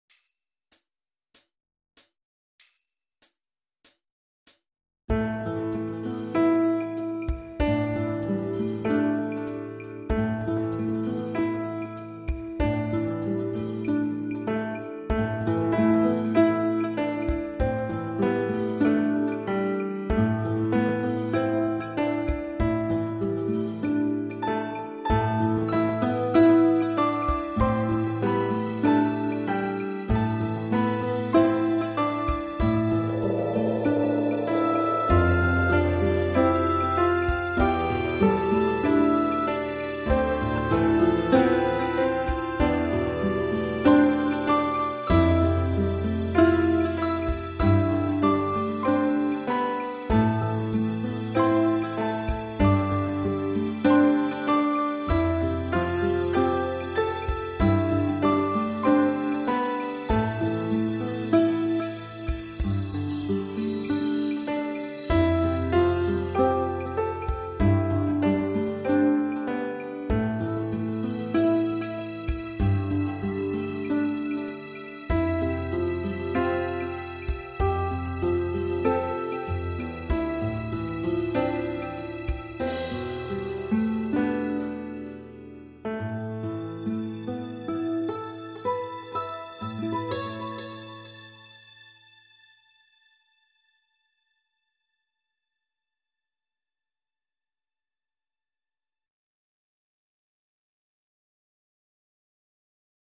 Accomp